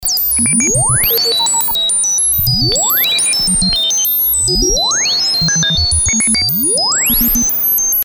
• Качество: 320, Stereo
красивые
Electronic
спокойные
без слов
инструментальные
космические